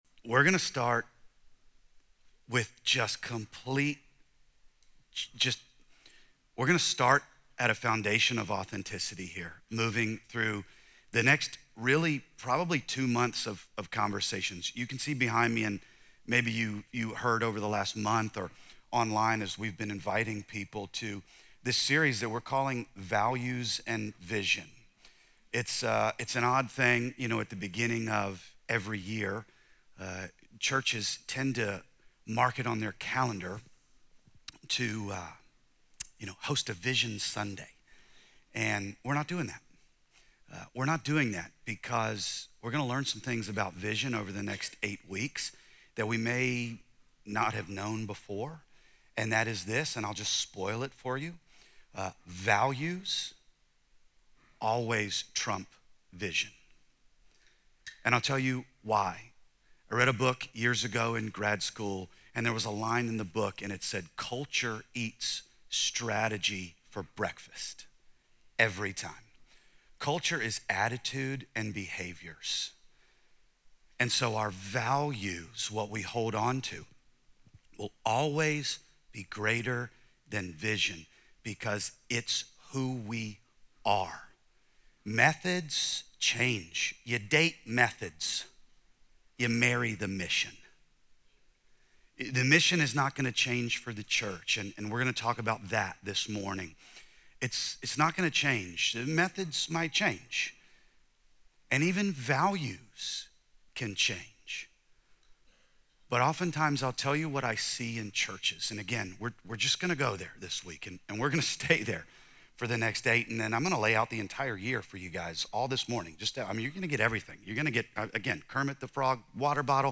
Let this sermon inspire you to move from faith on the inside to faith in